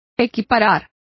Complete with pronunciation of the translation of equate.